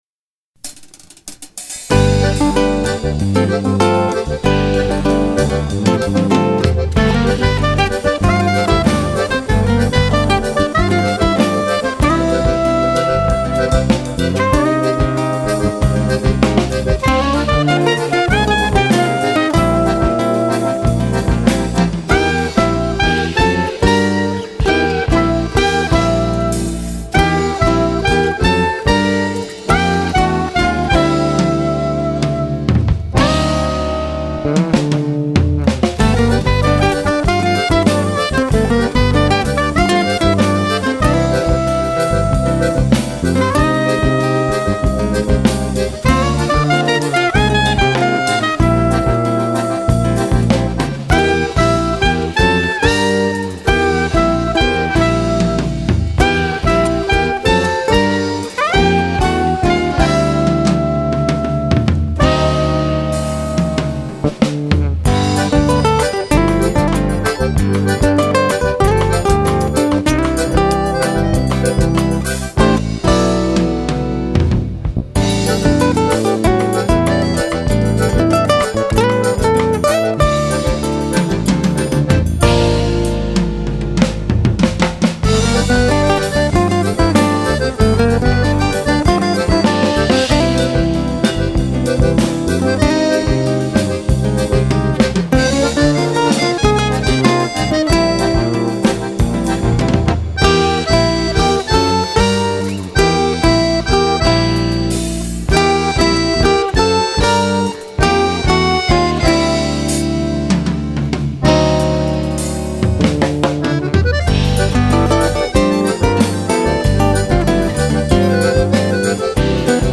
2202   03:36:00   Faixa:     Jazz
Bateria
Baixo Acústico, Percussão, Triângulo
Safona (8 baixos)
Guitarra, Violao Acústico 6
Sax Soprano